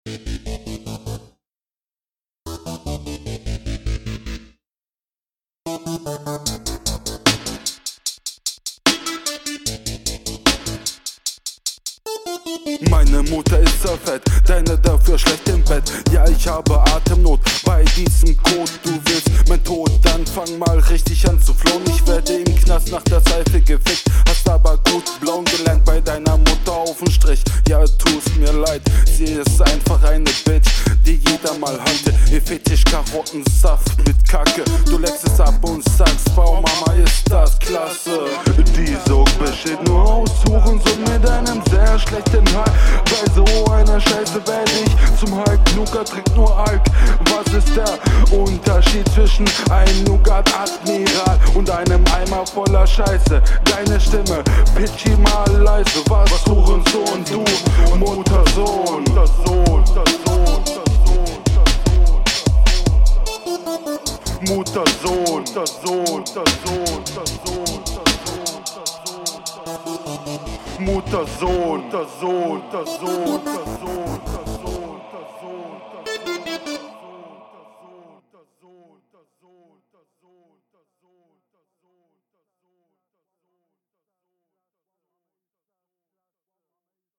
Dein Flow ist ähnlich mies wie zu deiner HR.